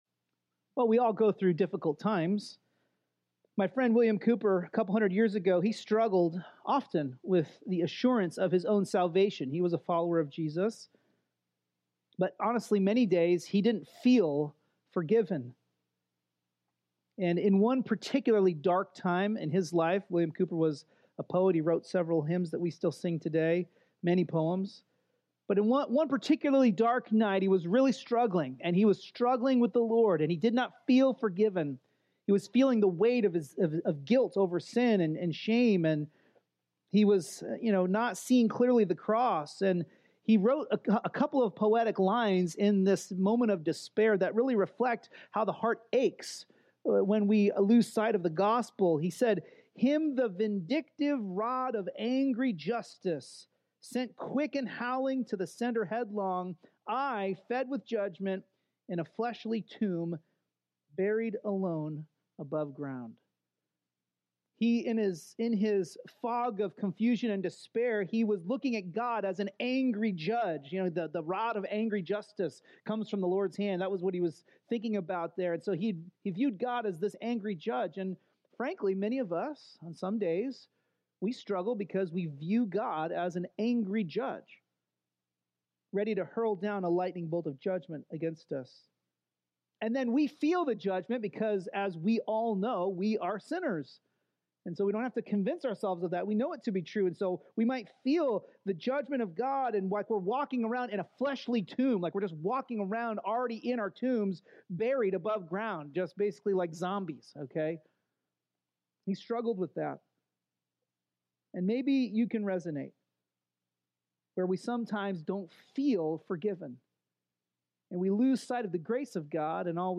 An expositional preaching series through the book of Hebrews